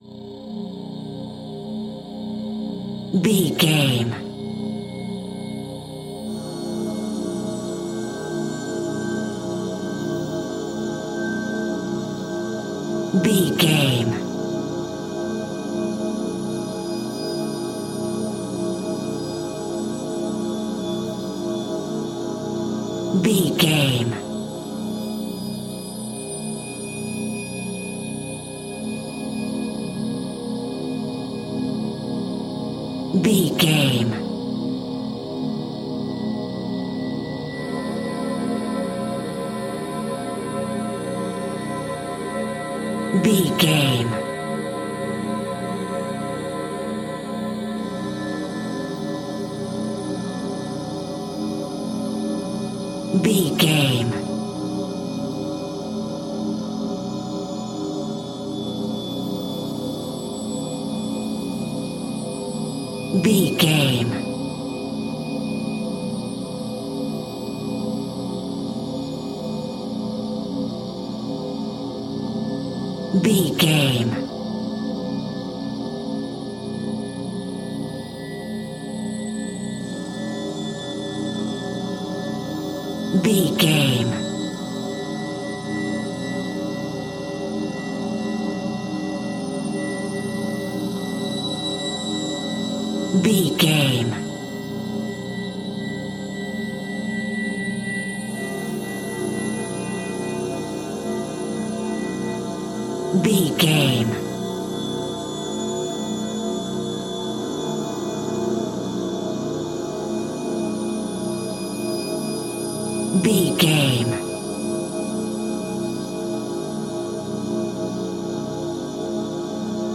In-crescendo
Thriller
Aeolian/Minor
scary
ominous
dark
suspense
eerie
strings
synth
ambience
pads